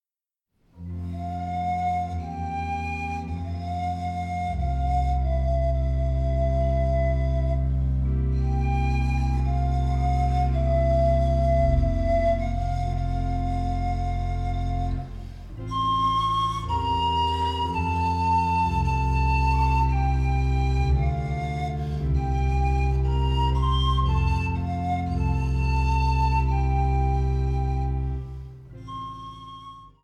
• kurzweilige Zusammenstellung verschiedener Live-Aufnahmen
Panflöte, Orgel